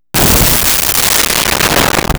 Explosion Cannon Fire 01
Explosion Cannon Fire 01.wav